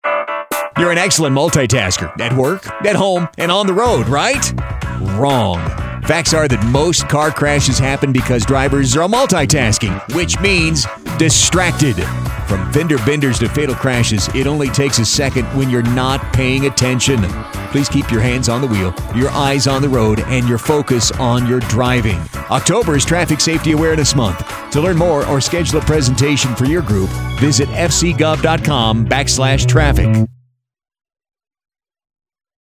Radio Ads#
multi-taskingPSA.mp3